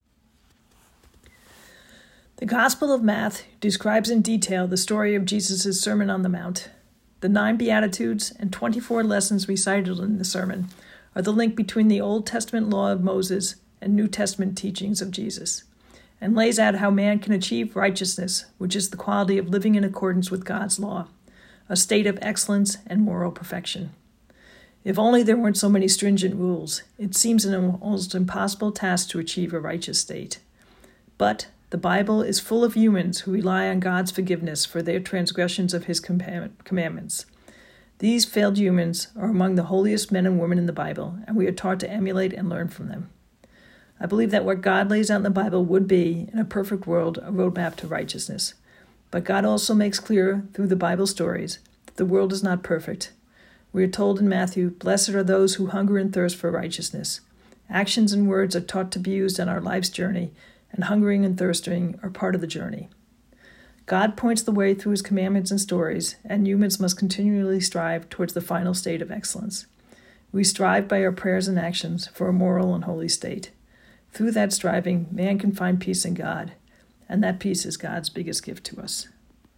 Sermon Podcast | Church of St. James the Less
Parishioners Reflect on the Beatitudes